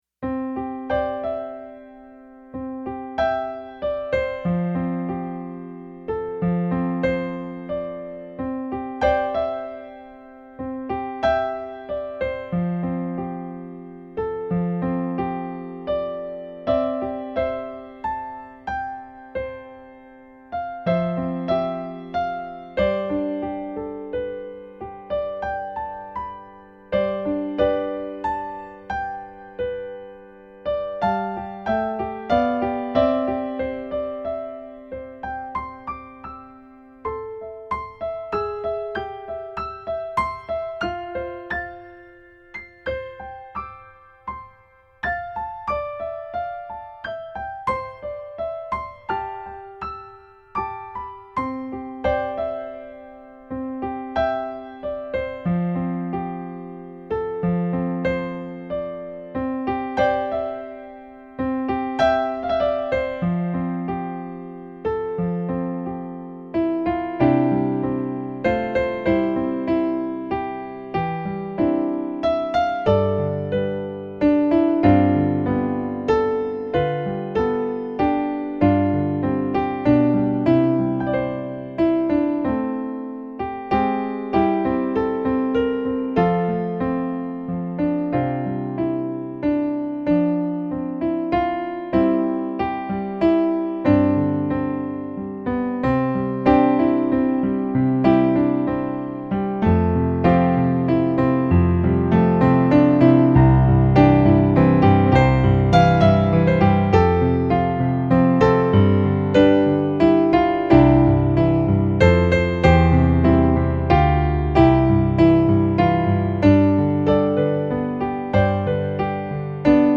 intermediate and advanced intermediate piano solo